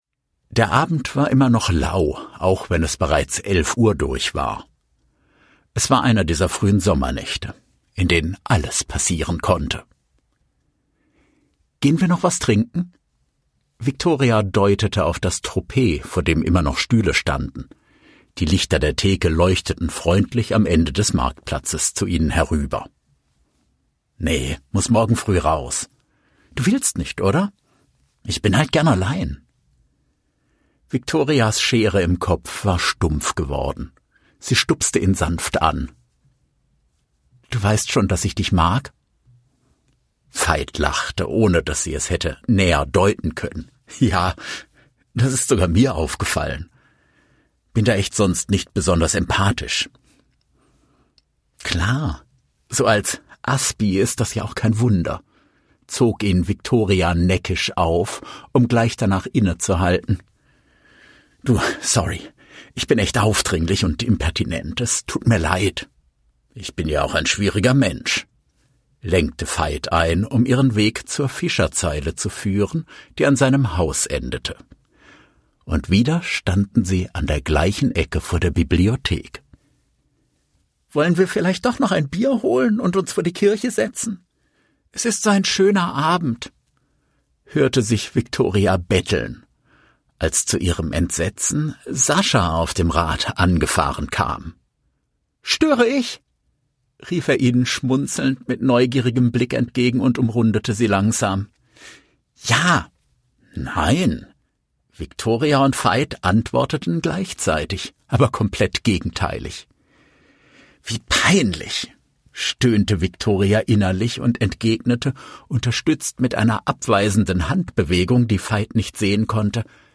Locutor
Hablante nativo